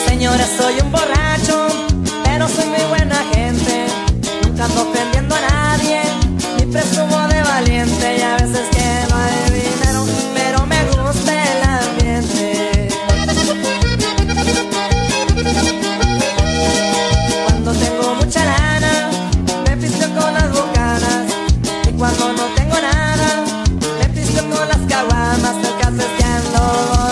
• Качество: 128, Stereo
мексиканские
Мексиканский рингтон